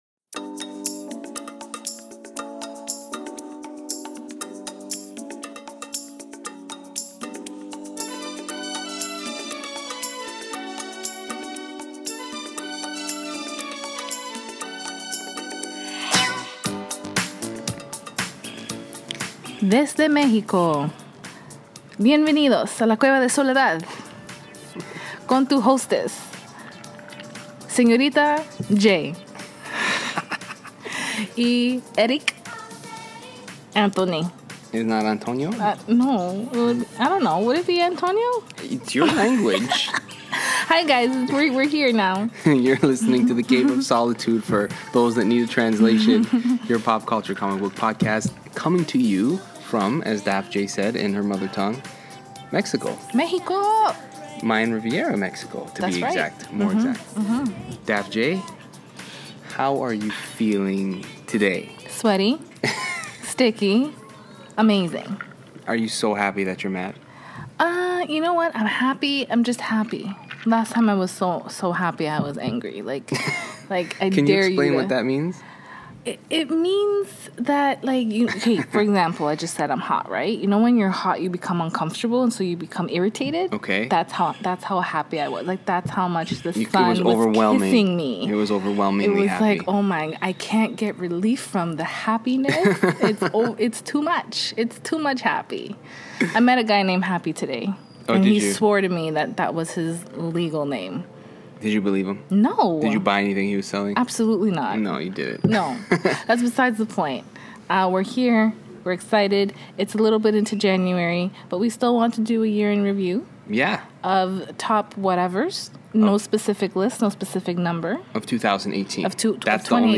Coming to you from the sunny beaches of Playa Del Carmen, Mexico